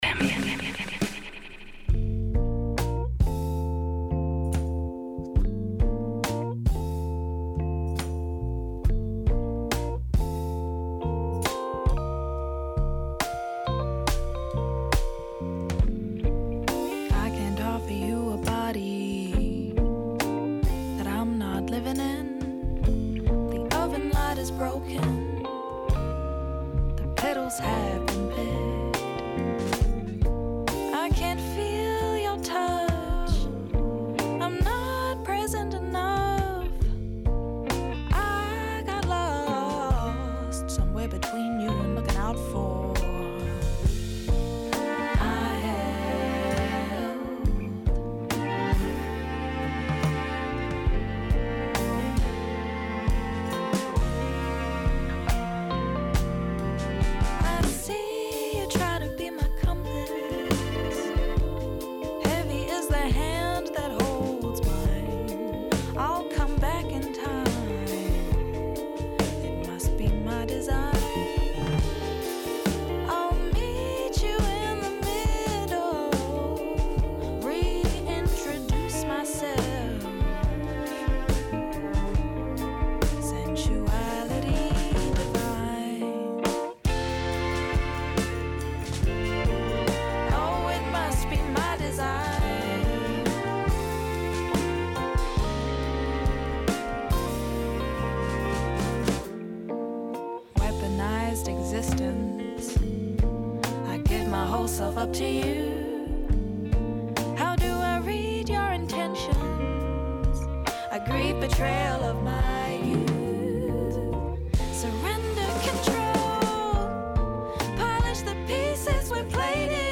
Guest Interview w